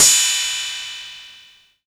soft-hitfinish.wav